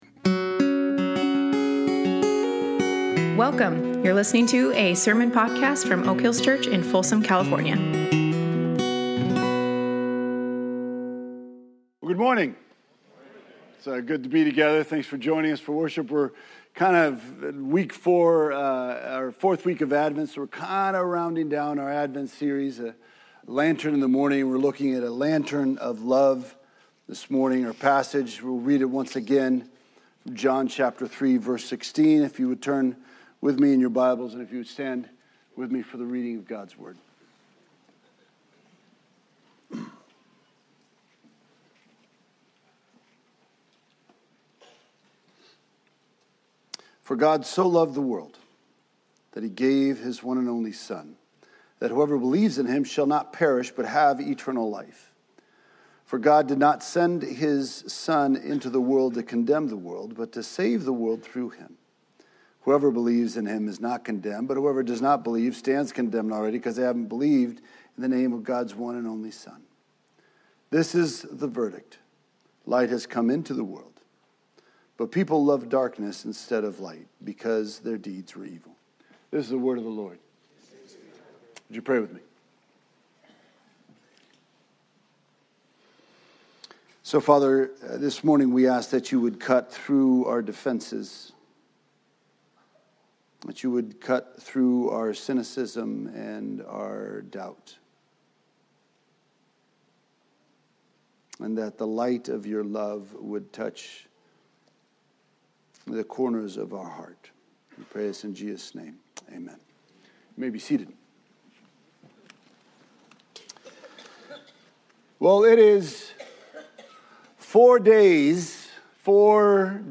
Oak Hills Church in Folsom, CA presents weekly messages about the reality of God and a vision of life under His guidance and leadership
Sermons